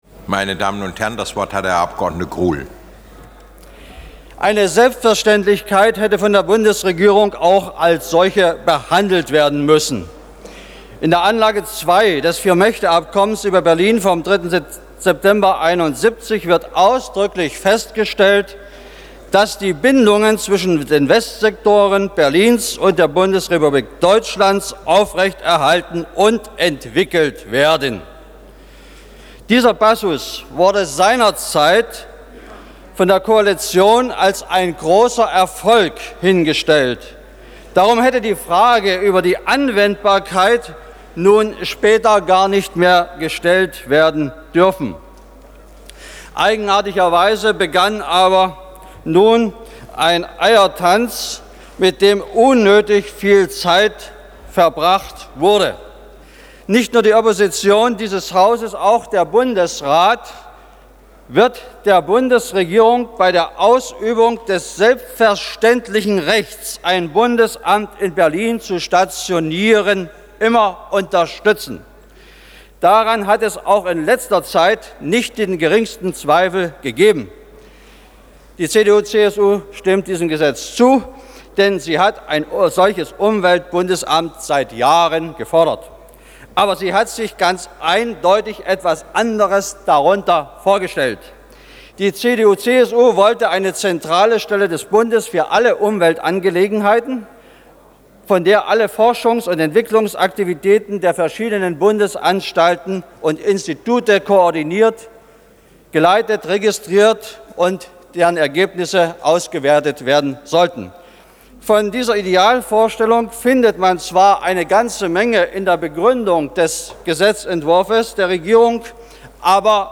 Rede von Herbert Gruhl (CDU/CSU)
Beratung über den Entwurf eines Gesetzes über die Einrichtung eines Umweltbundesamtes im Bundestag am 19.6.1974
bt_debatte_1974_gruhl.mp3